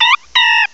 cry_not_pancham.aif